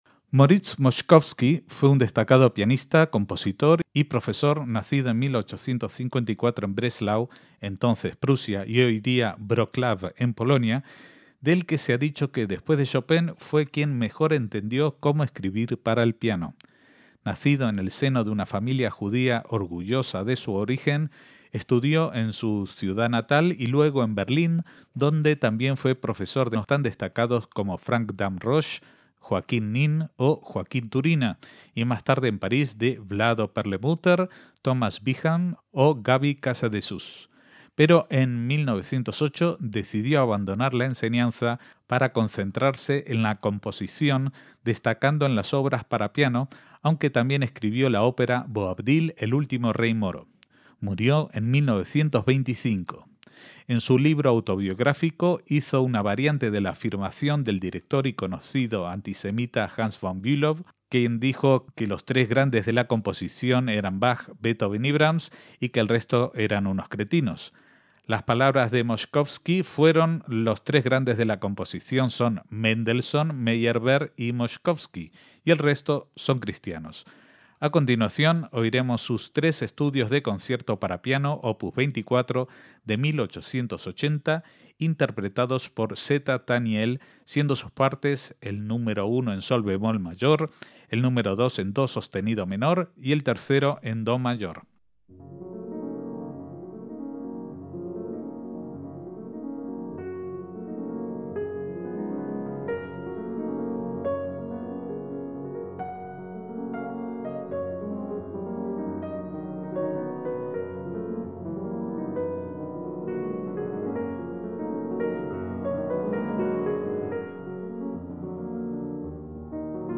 Los tres estudios para piano, opus 24, de Moszkowski
MÚSICA CLÁSICA - Moritz Moszkowski fue un compositor y pianista nacido en Brelau (actualmente Polonia) en 1854 y fallecido en París en 1925.